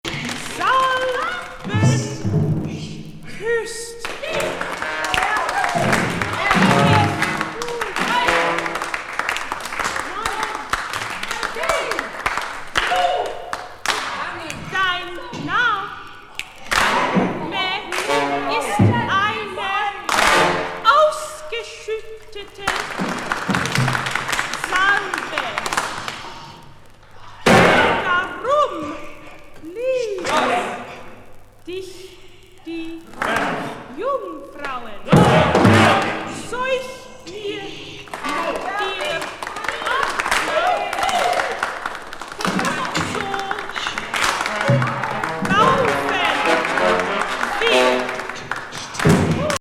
よる冗談めいた語りと、聴衆の拍手とオーケストラが交わる名(珍)曲!見ては